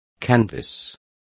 Complete with pronunciation of the translation of canvass.